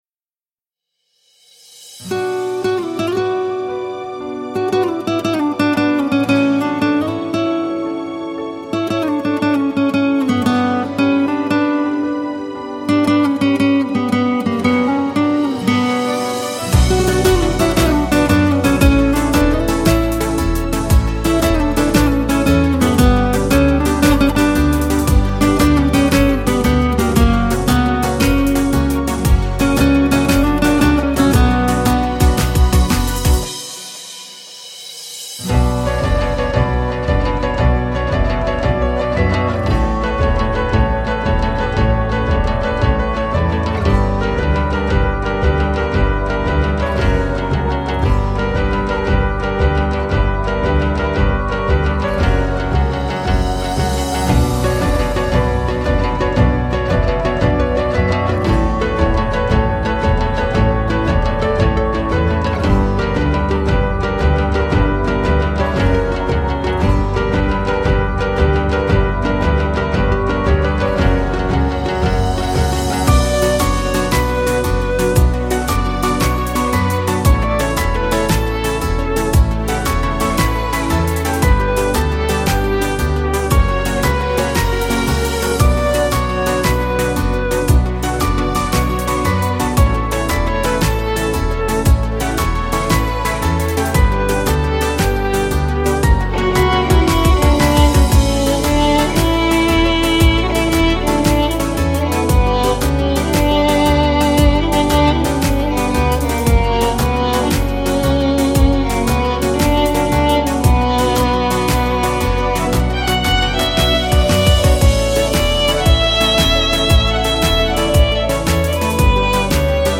بی کلام(نسخه اجرای زنده
سرود حماسی